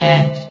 sound / vox_fem / ed.ogg
CitadelStationBot df15bbe0f0 [MIRROR] New & Fixed AI VOX Sound Files ( #6003 ) ...